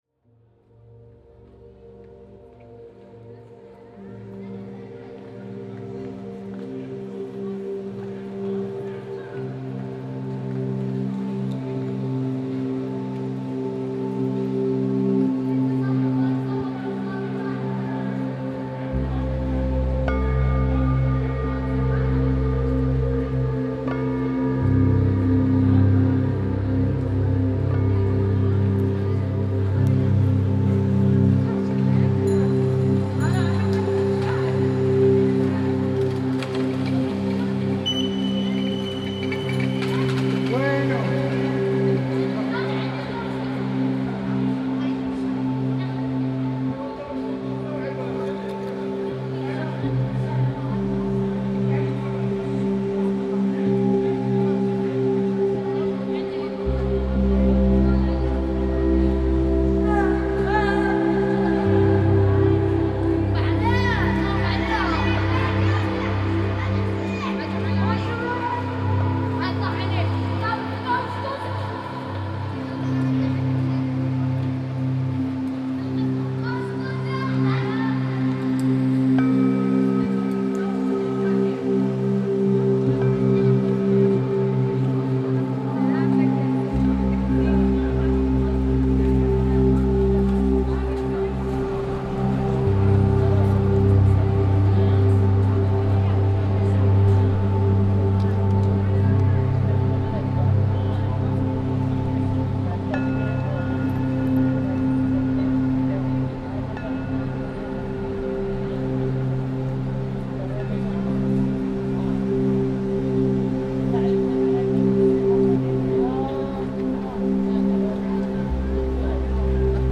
Bethlehem soundscape reimagined